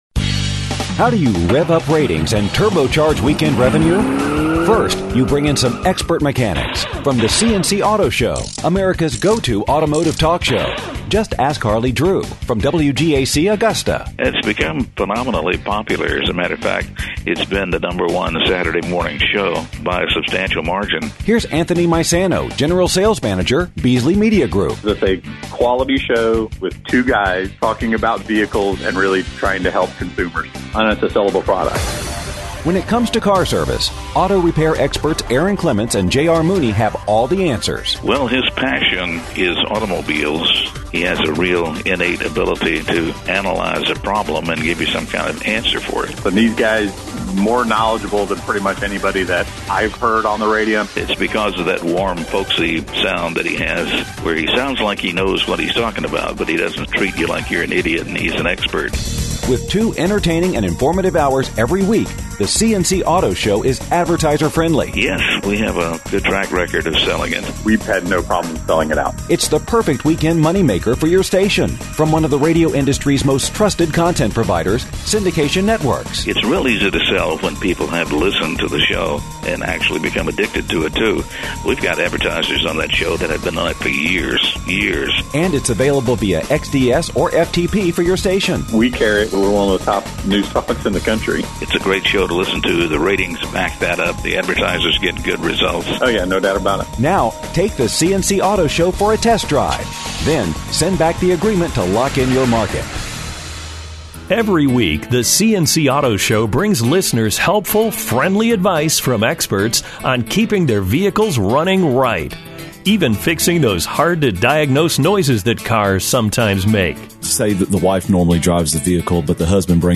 The C&C Auto Show is America’s Go-To Automotive Talk Show.